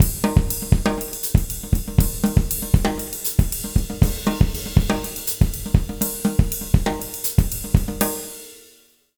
120SALSA02-R.wav